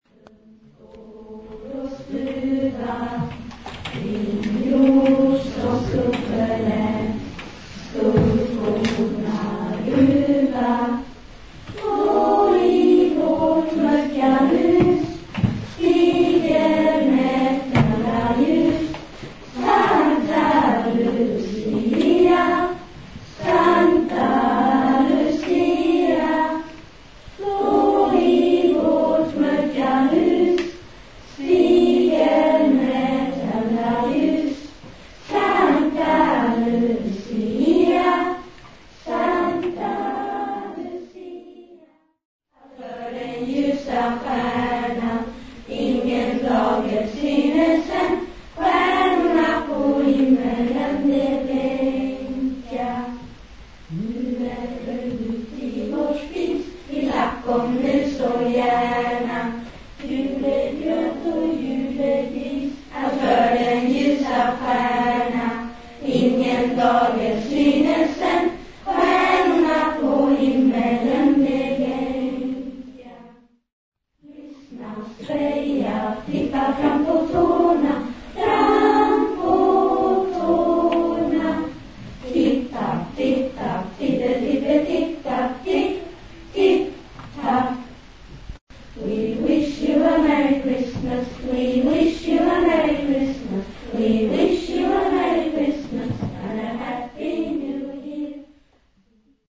Luciafest Forshems Scoutkår svarade för en stämningsfull tablå med sång och versläsning när Röda Korset arrangerade sin Luciafest i Österängs Bygdegård lördagen den 9 december.
luciapotpuriforweb.mp3